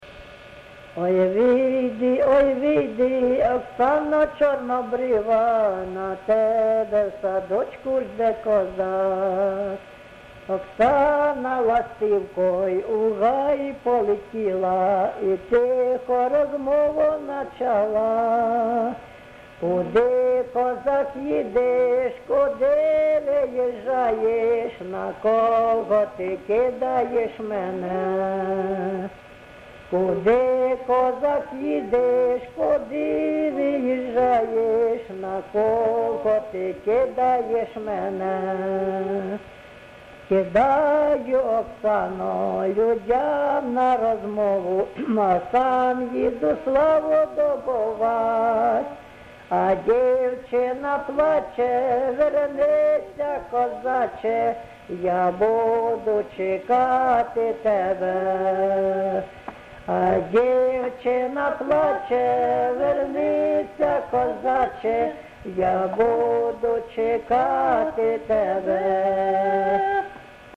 ЖанрПісні з особистого та родинного життя
МотивНещаслива доля, Розлука, Прощання, розставання, Журба, туга
Місце записум. Антрацит, Ровеньківський район, Луганська обл., Україна, Слобожанщина